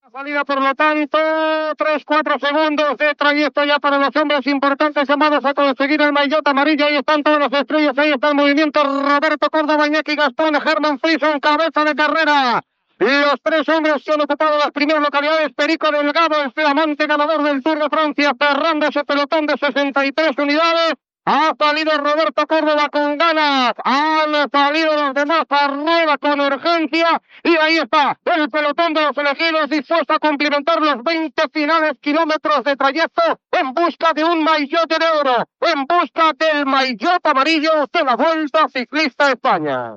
Narració de l'etapa pròleg de la Vuelta a España, que es es disputa a La Corunya.
Esportiu